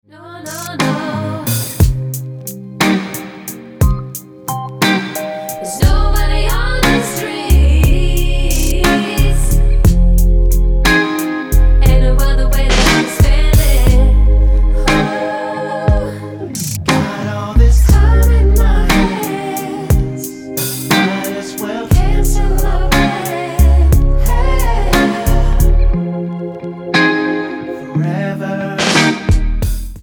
--> MP3 Demo abspielen...
Tonart:Ab mit Chor